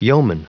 Prononciation du mot yeoman en anglais (fichier audio)
Prononciation du mot : yeoman